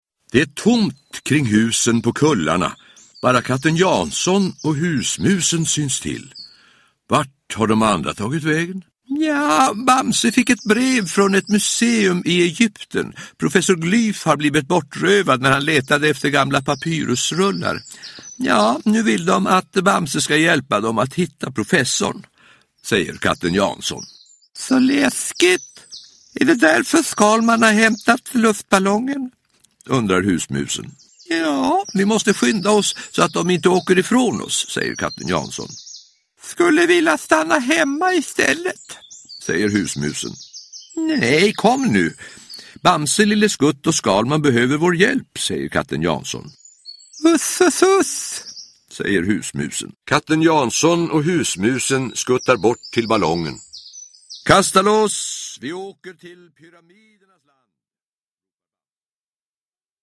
Uppläsare: Olof Thunberg